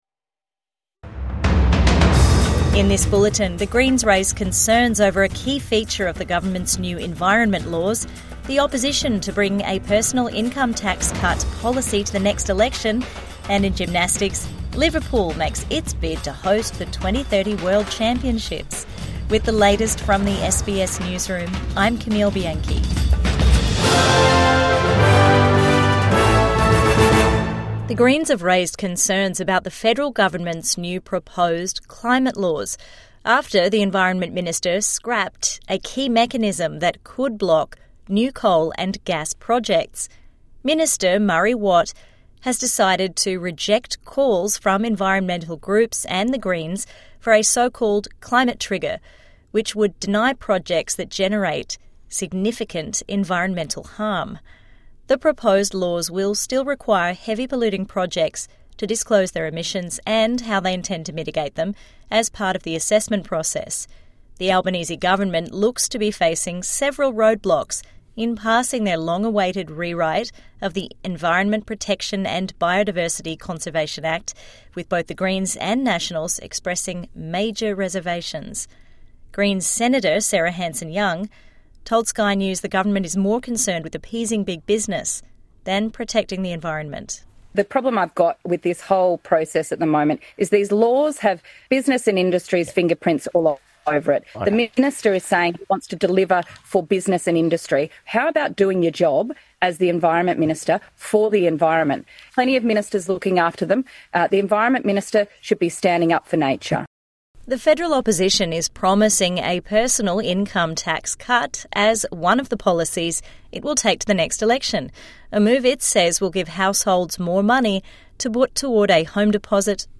Greens raise alarm over new environment laws | Evening News Bulletin 23 October 2025